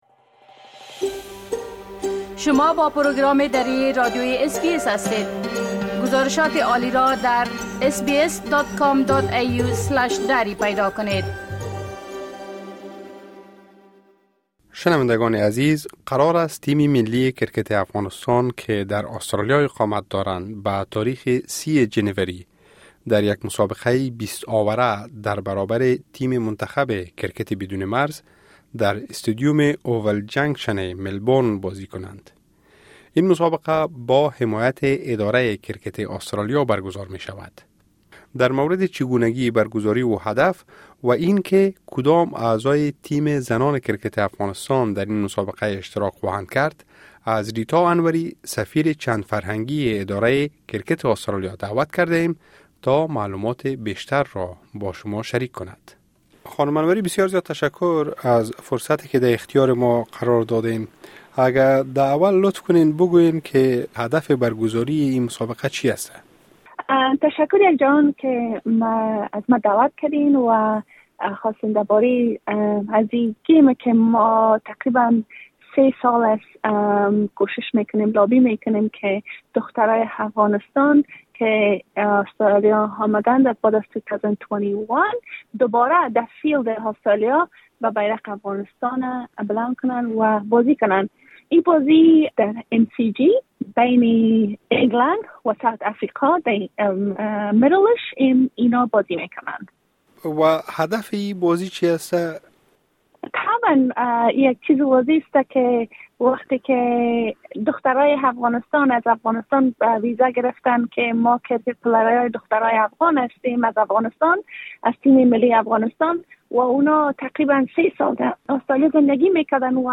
برگزاری مسابقه تیم کرکت زنان افغانستان در ملبورن؛ مصاحبه